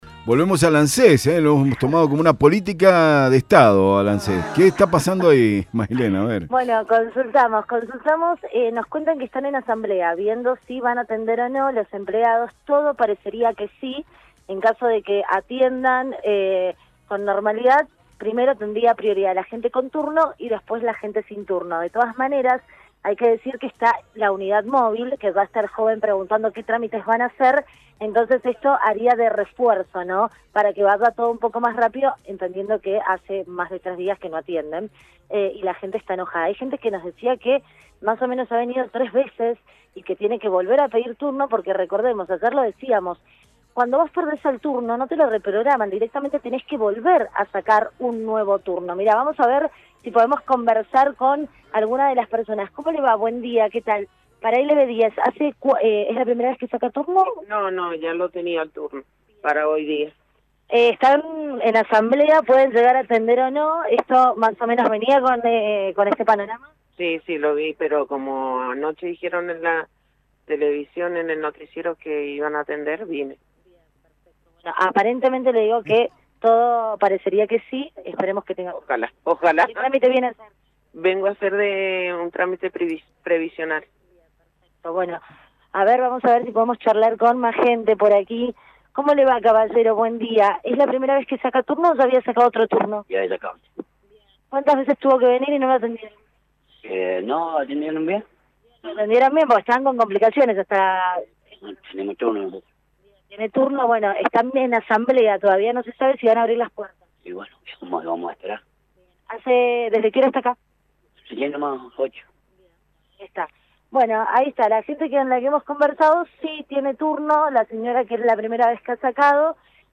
LVDiez - Radio de Cuyo - Móvil de LVDiez desde Anses de calle Eusebio Blanco, Cdad